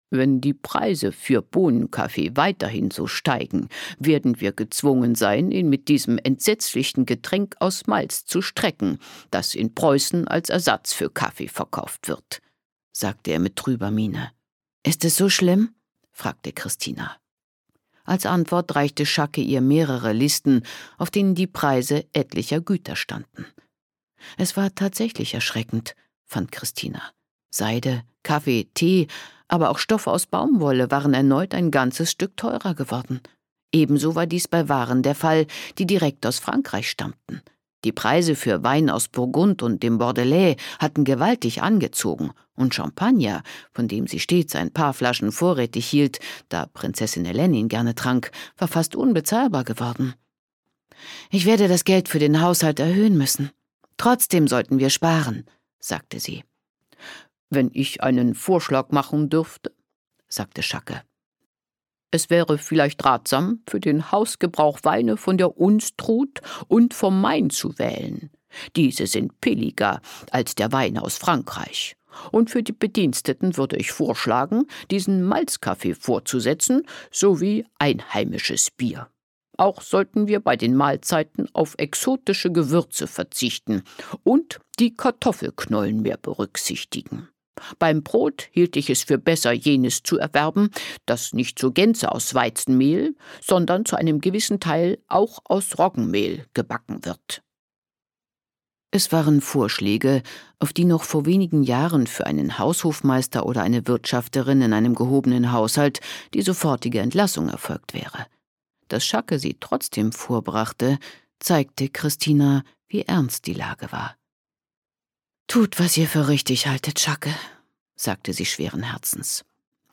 Gekürzte Lesung
Ihre volle, melodiöse Stimme leiht sie zudem zahlreichen Hörbüchern.